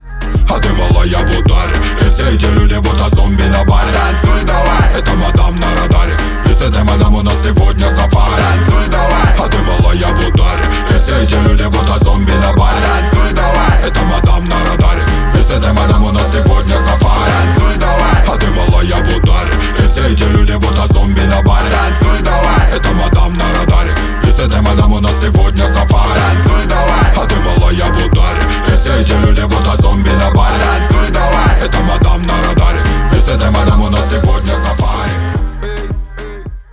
Поп Музыка # Танцевальные
ритмичные